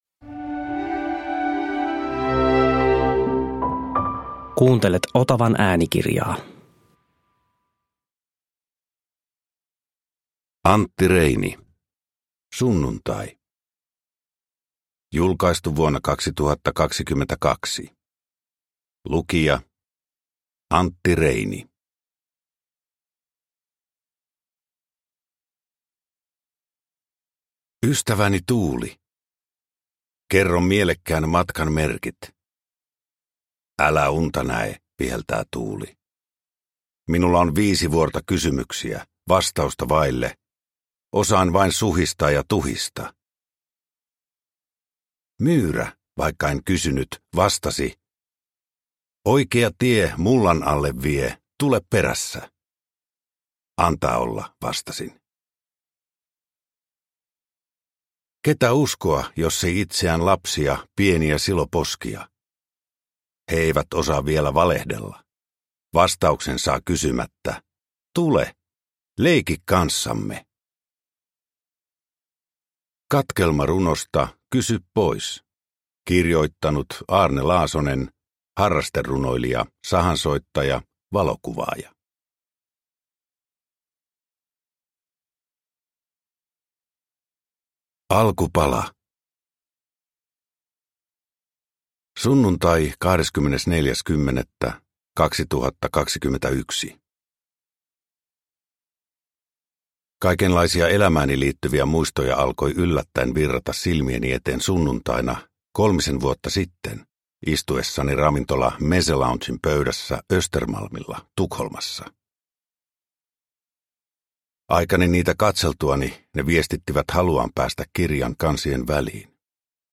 Uppläsare: Antti Reini